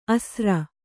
♪ asra